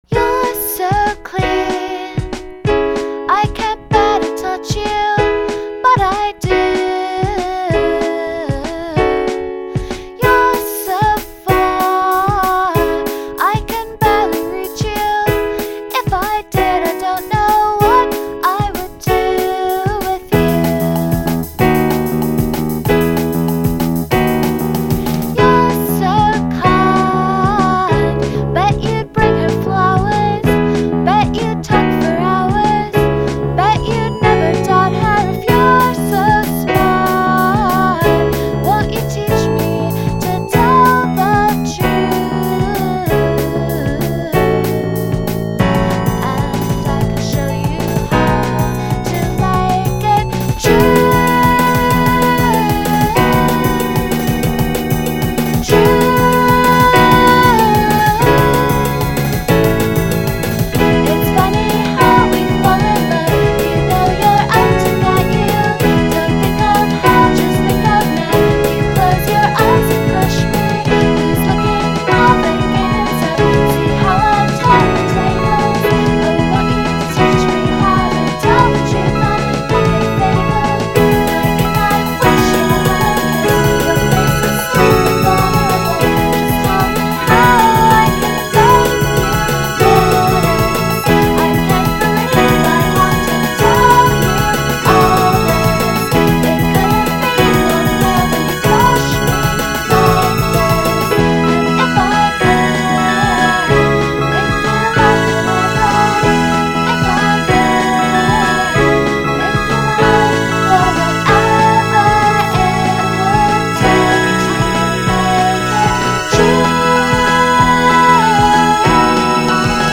e major
this is just a demo built to support the melody. i wanted to write a fast pop song. am absolutely sure this melody on the chorus (d) part is lifted from another song but i can't think of what it is. work in progess....
• The verse melody on this song is infectious.
• I don't know if you should throw the 16th on the hihat in between the first and second stanzas.
Good percussion on the C section, and throughout, really.
• Super awesome synth strings, though the arrangement could be a mite more engaging, I think.
• Cowbell = awesome.
I think it's that the arrangement just keeps piling up with keyboards and stuff and the dynamics kind of max out.